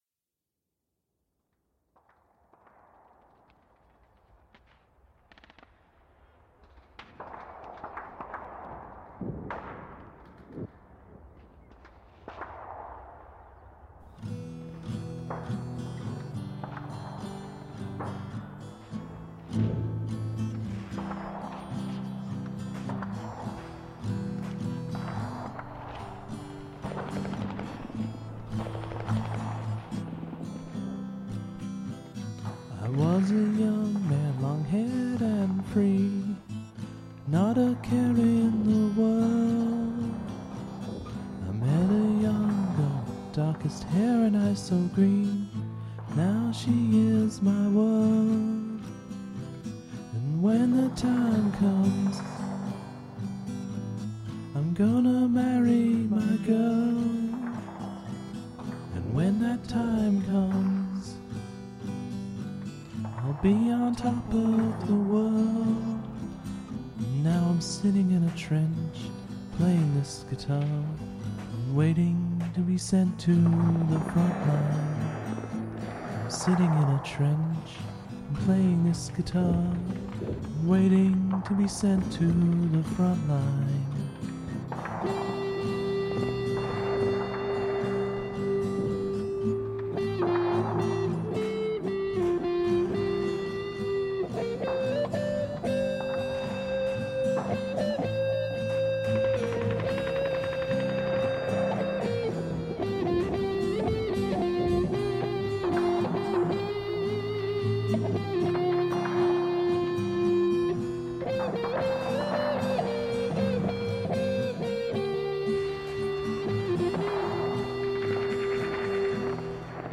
Use of the sound of gunfire